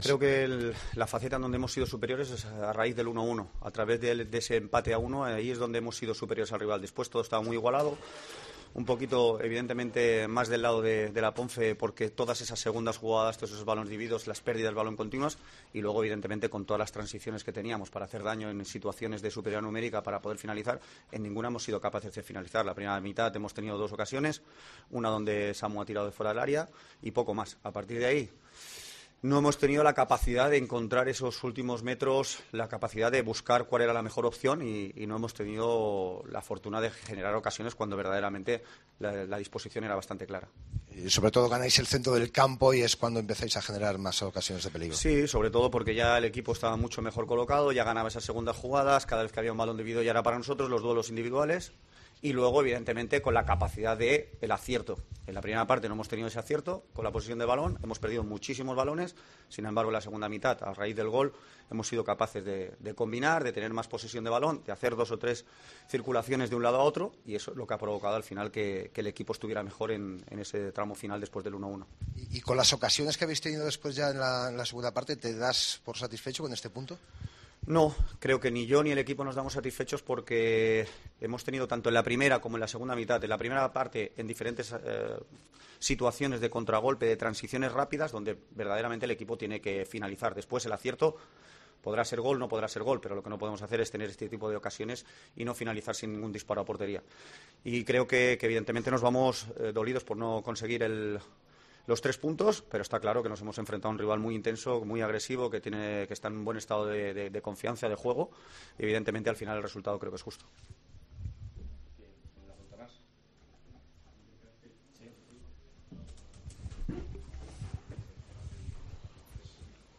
POSTPARTIDO
Escucha aquí las palabras del entrenador del Girona, Josep Lluís Martí, tras el empate en El Toralín de Ponferrada 1-1 ante la Deportiva